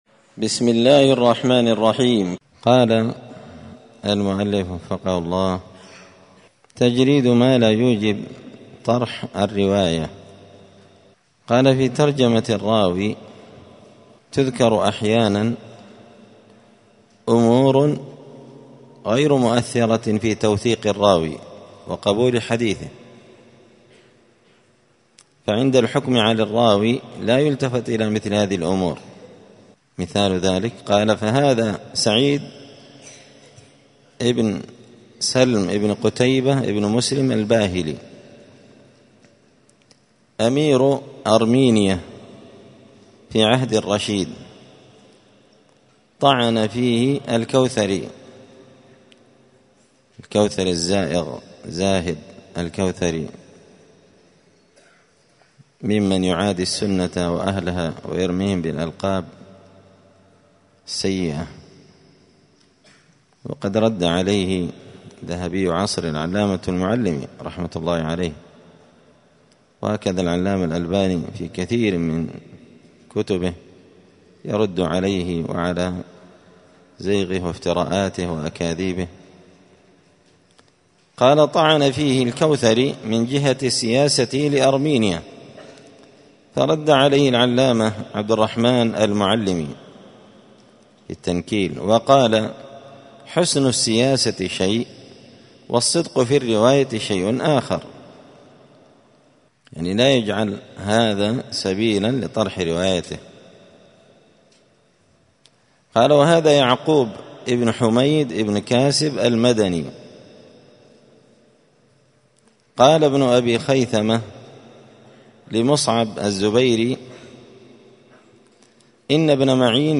*الدرس التاسع عشر (19) تجريد مالا يوجب طرح الرواية*